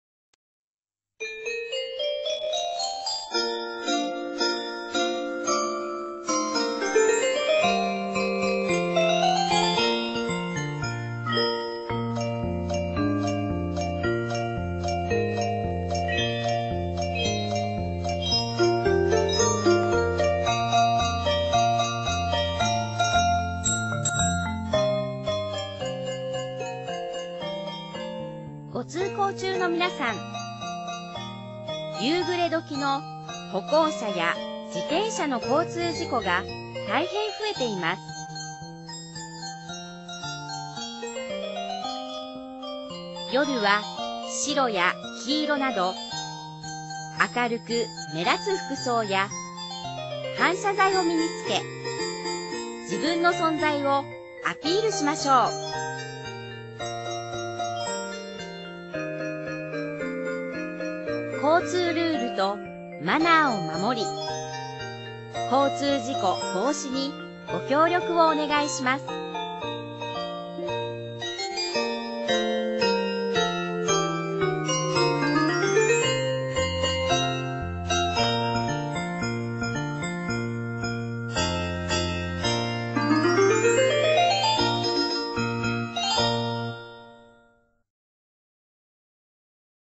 内容は、「ピーポくんのうた」オルゴールバージョンをBGMに、歩行者・自転車など、テーマ別に大切なルールやマナーを呼びかけています。